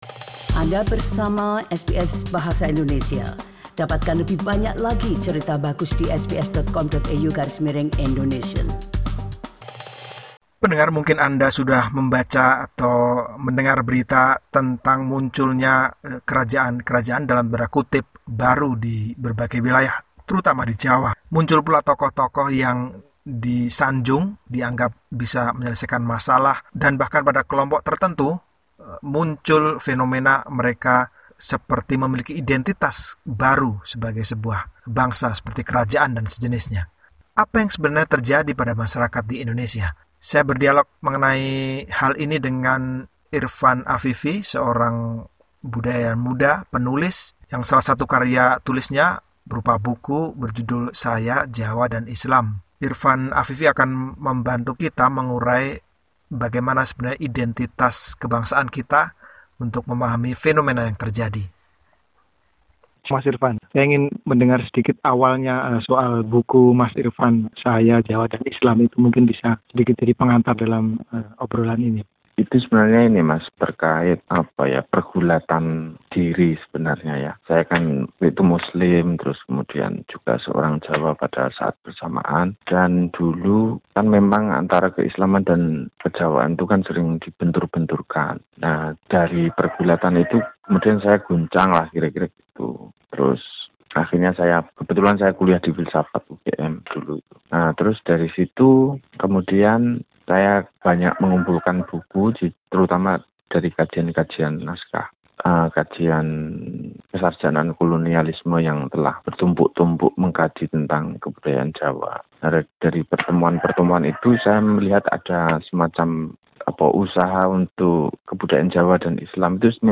Perbincangan dengan budayawan muda dan penulis buku berjudul "Saya